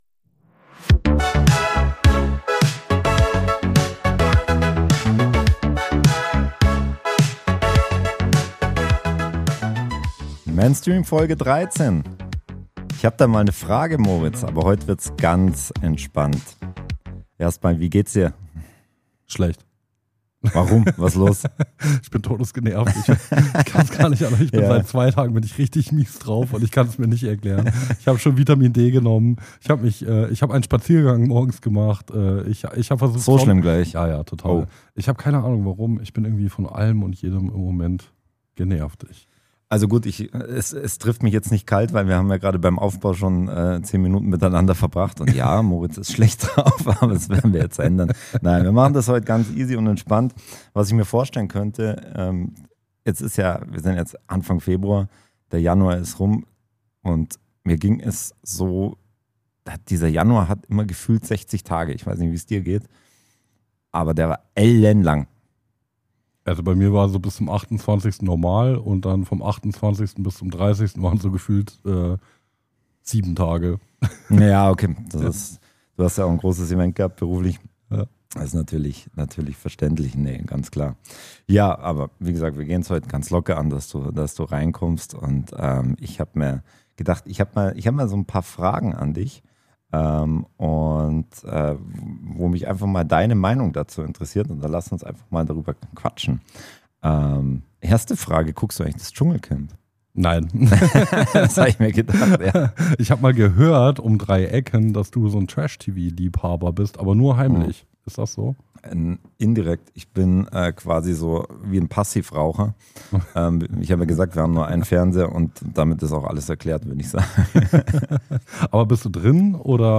Einfach ein ehrliches Gespräch, so wie es oft am Küchentisch entsteht.